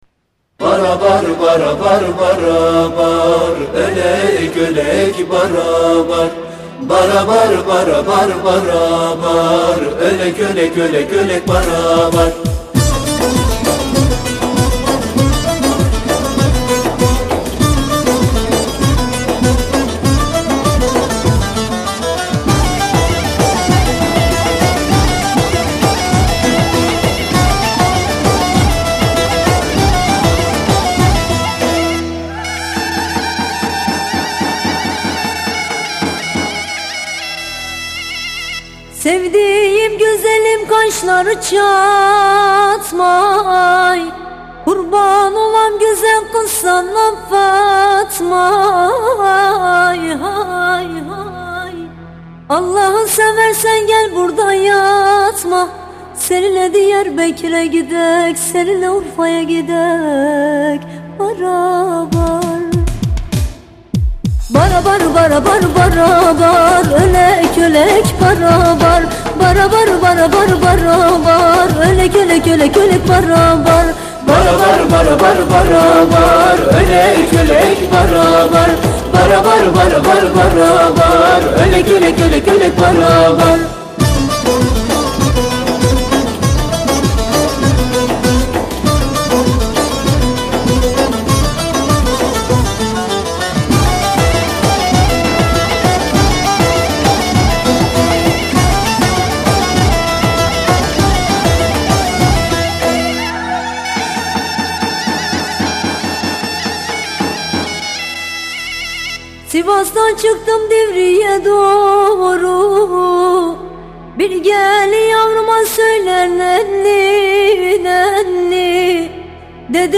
Sivas Türküleri Dinle